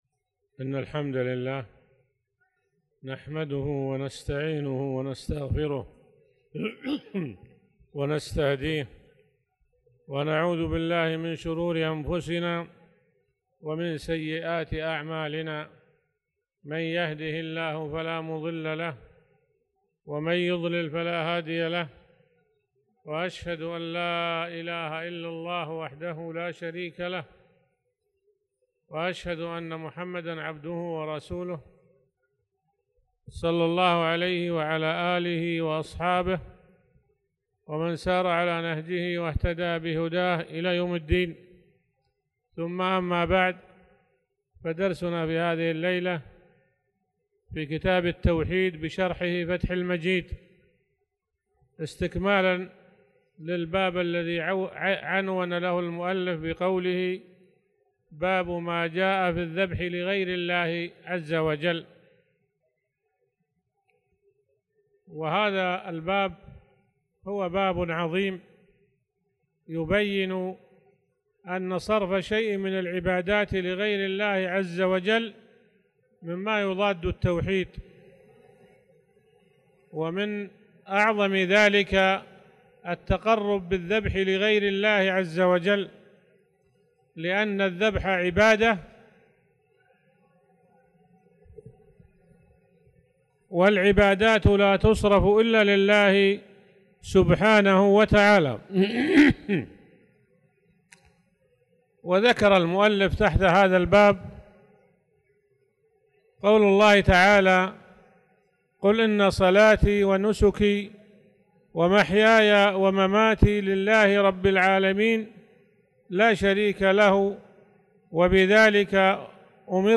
تاريخ النشر ١٣ ذو القعدة ١٤٣٧ هـ المكان: المسجد الحرام الشيخ